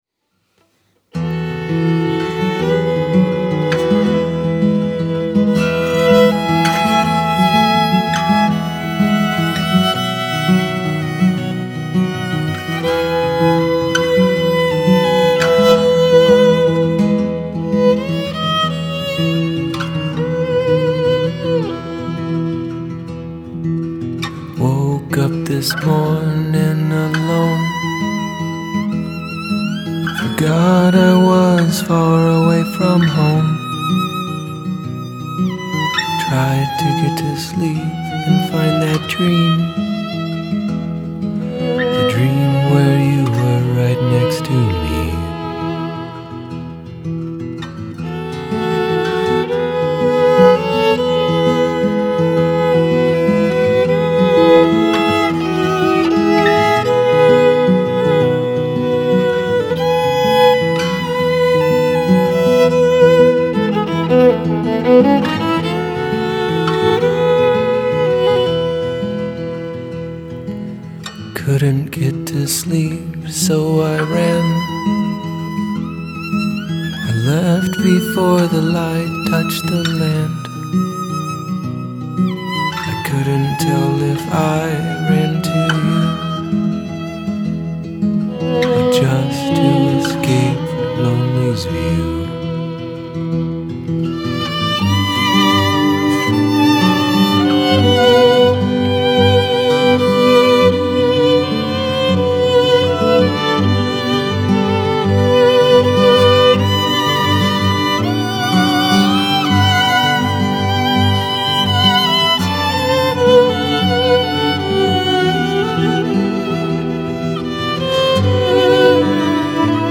is playing violin on this one. This was my first project writing and recording strings from around 2012.